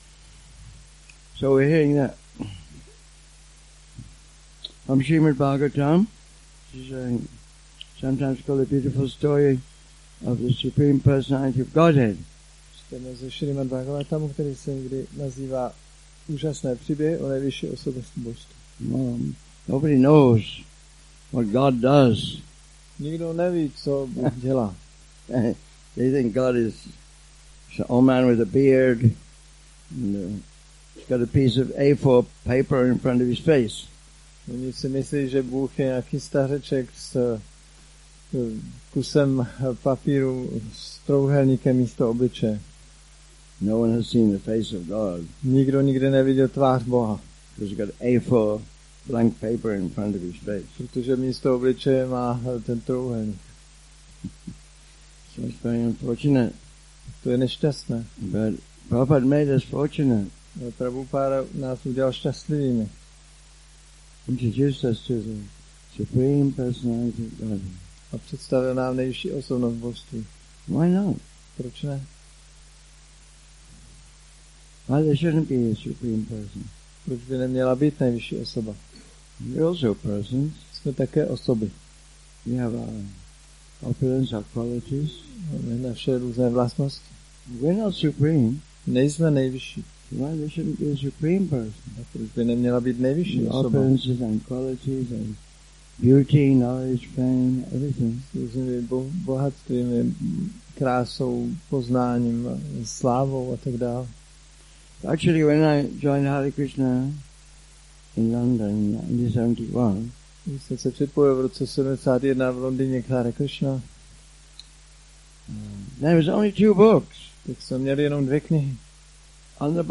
Přednáška SB-10.54.46